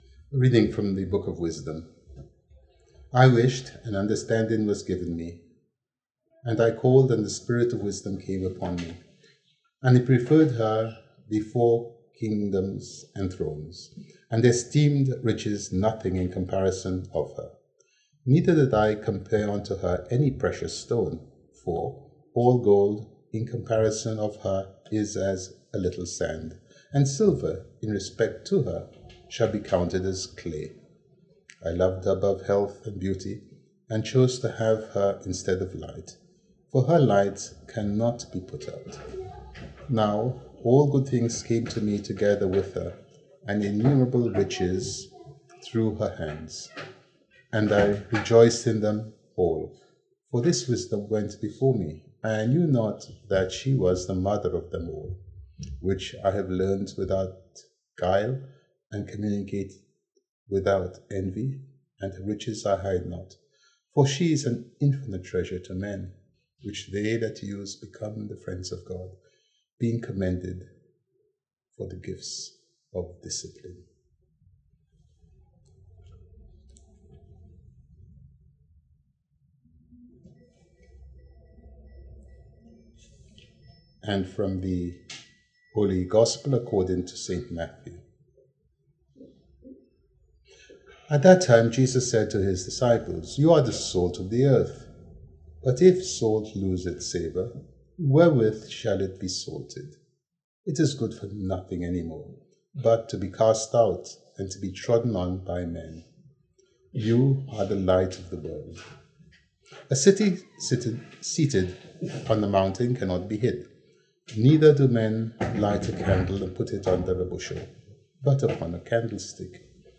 Homily: Accept suffering to save lives
The Mass was offered in London, and the homily was recorded.